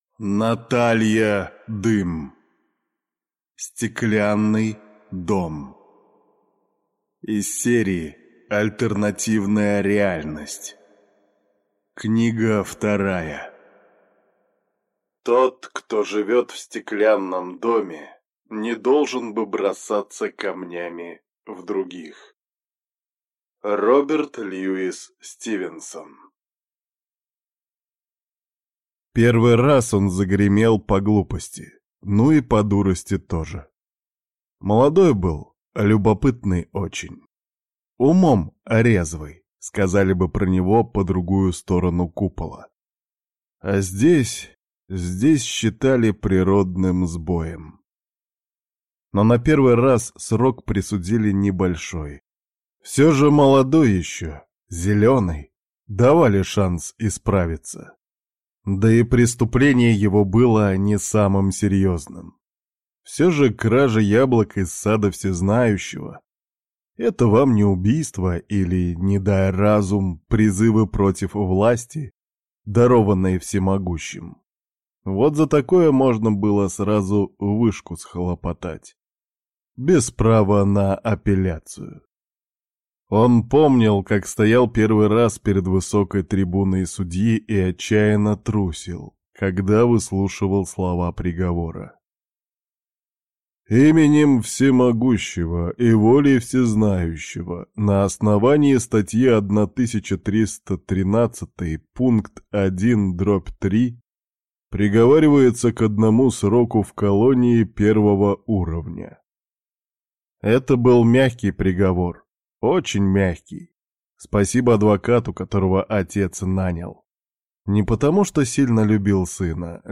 Аудиокнига Стеклянный дом | Библиотека аудиокниг